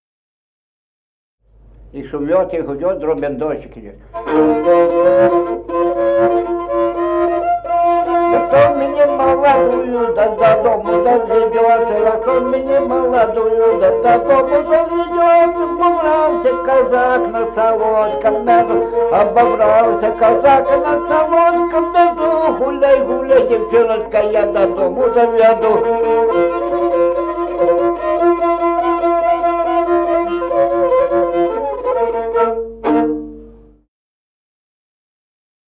Музыкальный фольклор села Мишковка «И шумёть, и гудёть», репертуар скрипача.